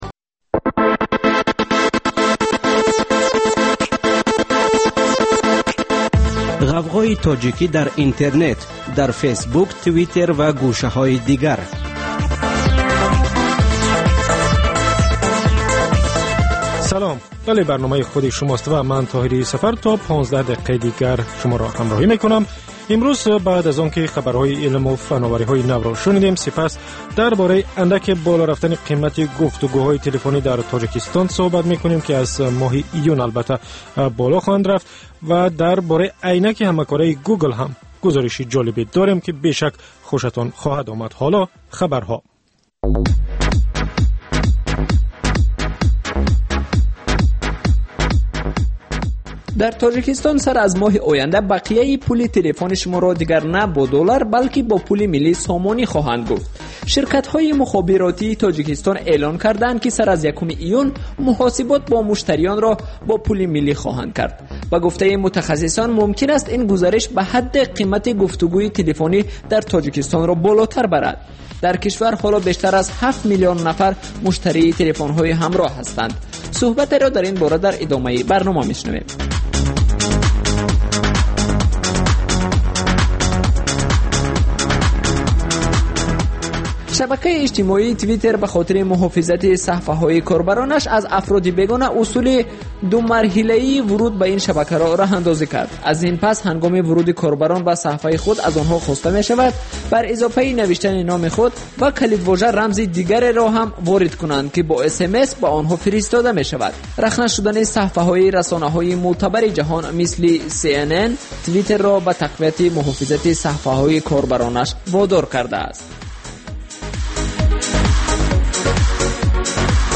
Талоши чеҳранигорӣ аз афроди хабарсоз ва падидаҳои муҳими Тоҷикистон, минтақа ва ҷаҳон. Гуфтугӯ бо коршиносон.